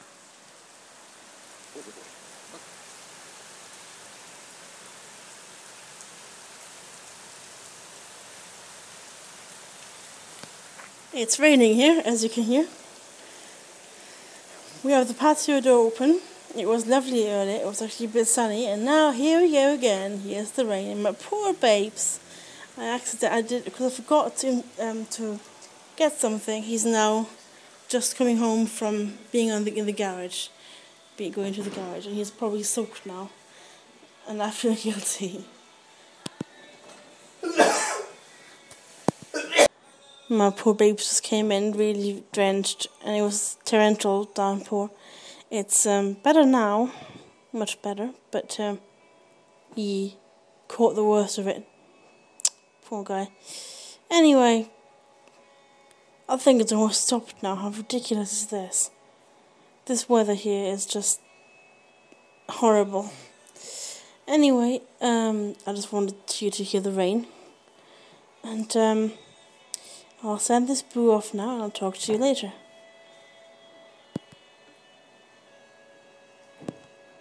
Downpour boo